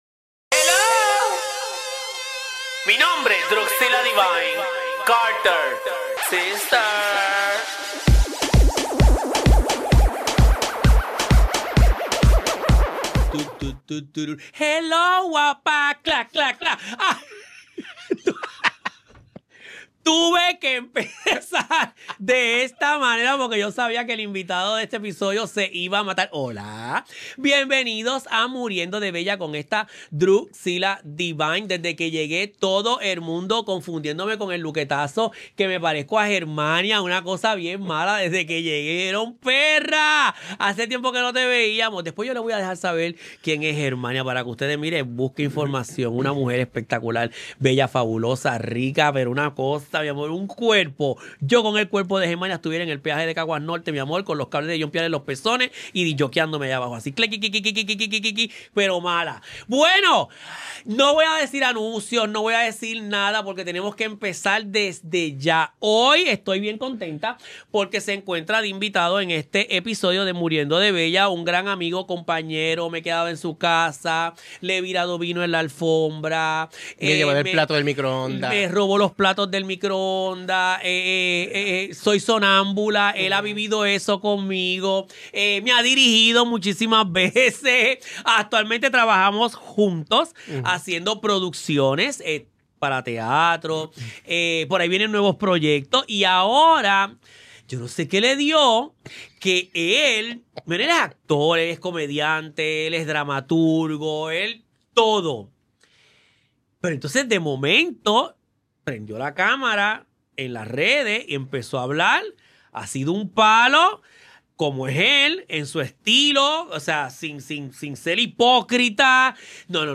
Grabado en los estudios de GW-Cinco y somos parte del GW5 Network.